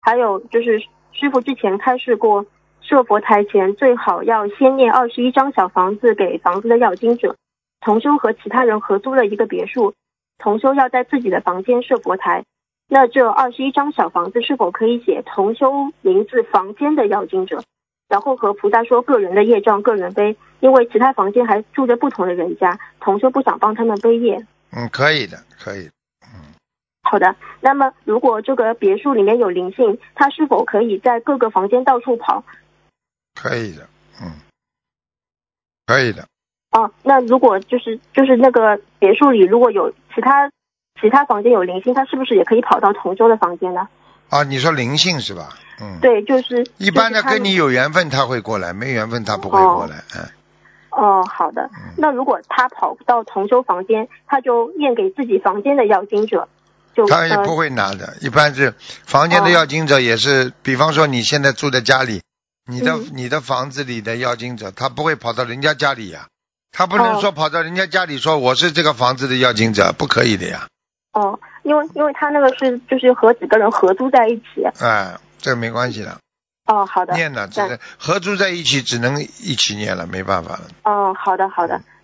目录：☞ 2019年10月_剪辑电台节目录音_集锦